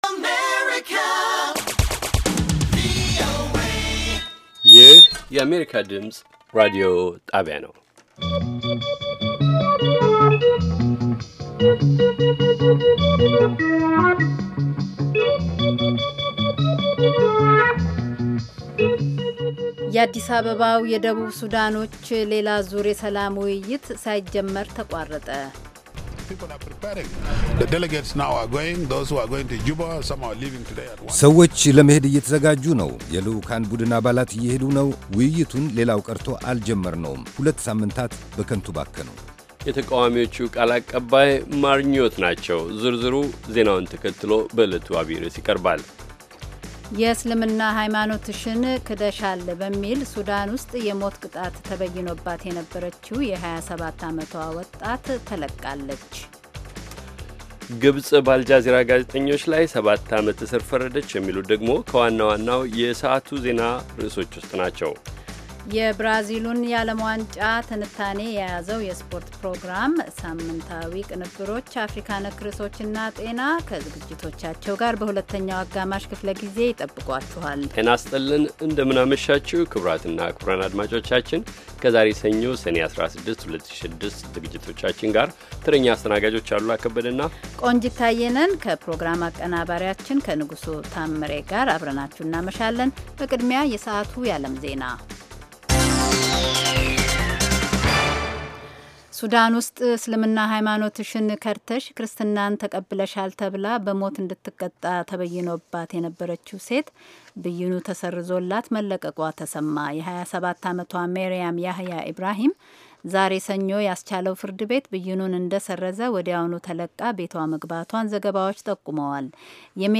ዘወትር ከምሽቱ ሦስት ሰዓት ላይ ኢትዮጵያና ኤርትራ ውስጥ ለሚገኙ አድማጮች በአማርኛ የሚተላለፉ ዜናዎች፣ ቃለመጠይቆችና ሌሎችም ትኩስ ዘገባዎች፤ እንዲሁም በባሕል፣ በጤና፣ በሴቶች፣ በቤተሰብና በወጣቶች፣ በፖለቲካ፣ በግብርና፣ በንግድ፣ በተፈጥሮ አካባቢ፣ በሣይንስ፣ በቴክኖሎጂ፣ በስፖርት፣ በሌሎችም አካባቢያዊና የመላ አፍሪካ ጉዳዮች ላይ ያተኮሩ መደበኛ ዝግጅቶች የተካተቱባቸው የአንድ ሰዓት ዕለታዊ ሥርጭቶች